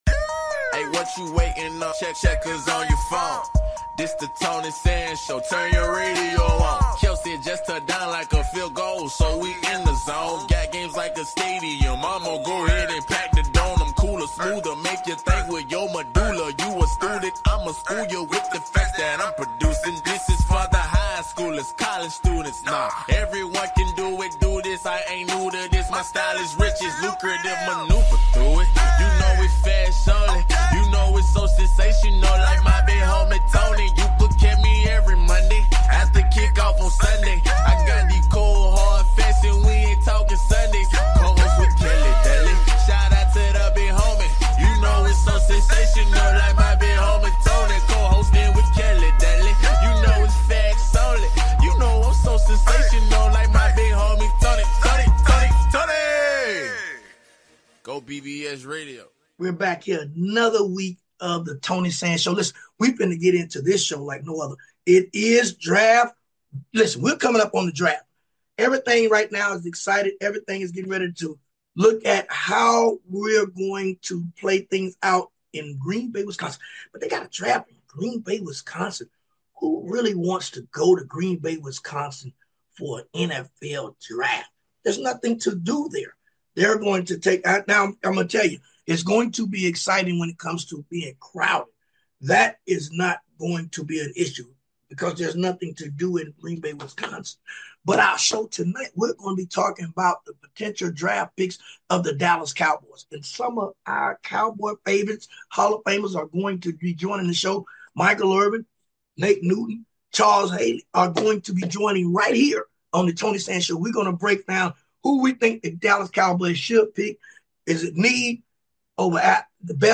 Tony Sands is joined by guests and friends, Football Hall of Famer Charles Haley, Michael Irvin and Nate Newton as they discuss the current state of the Dallas Cowboys.
Talk Show